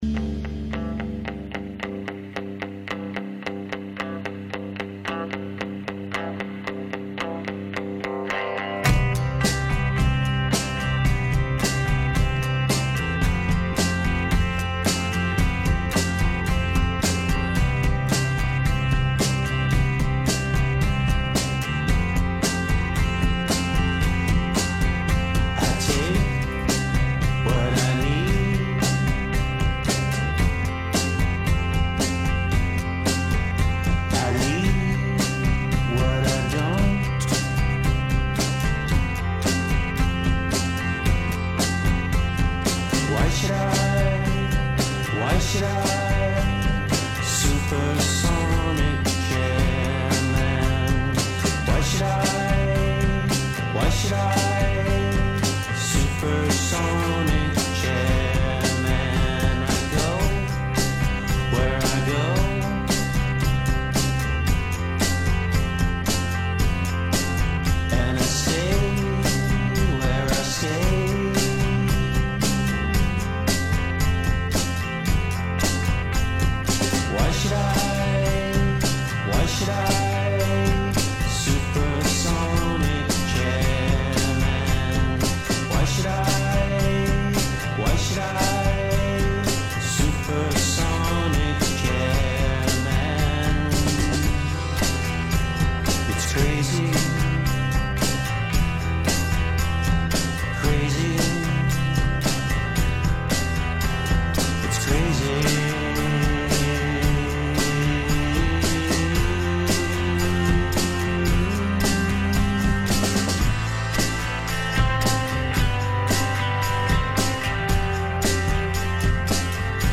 called into WTSQ's afternoon show